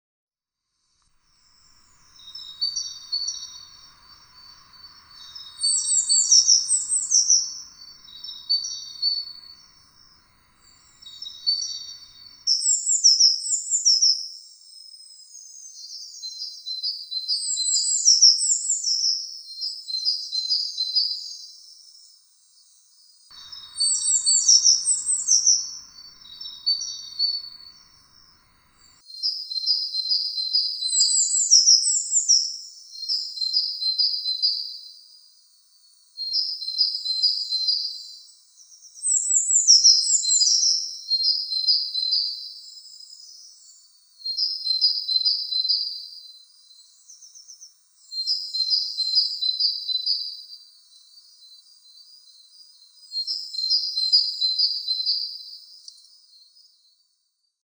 Звуки синицы
Мелодичное пение синицы в природе